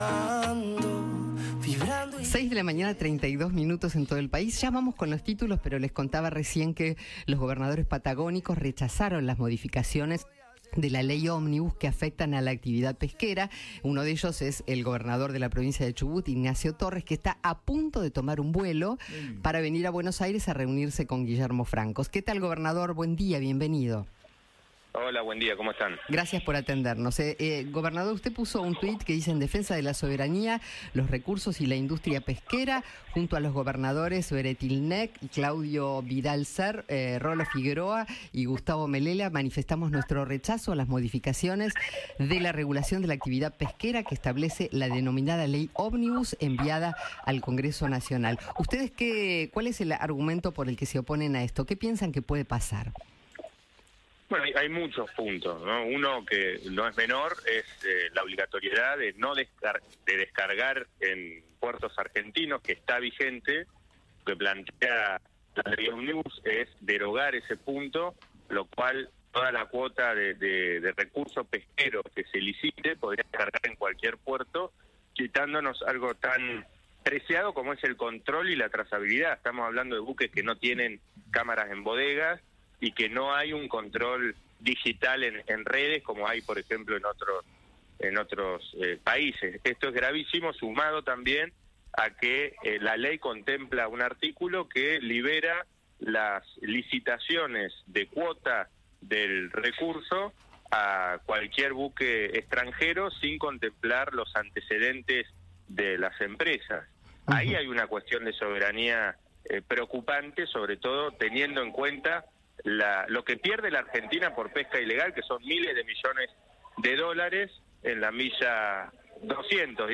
Ignacio Torres, gobernador de Chubut, dialogó con el equipo de Alguien Tiene que Decirlo sobre las modificaciones de la ley ómnibus que afectan a la actividad pesquera.